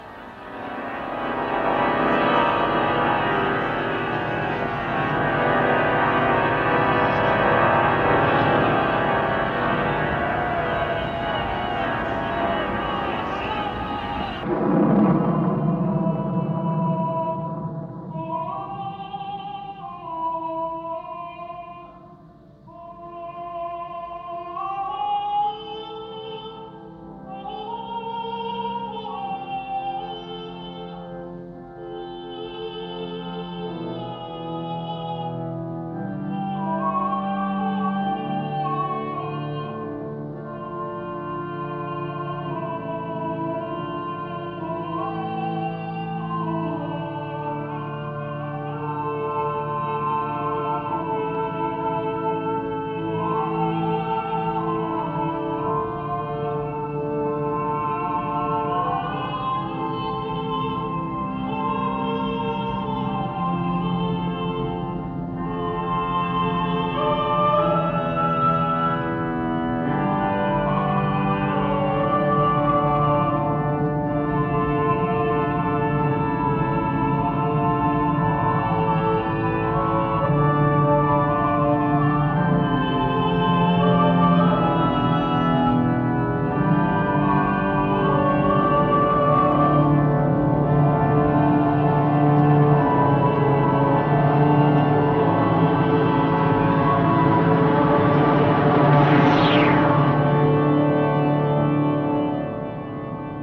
a kind of early music version of the second movement